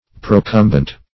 Procumbent \Pro*cum"bent\, a. [L. procumbens, -entis, p. pr. of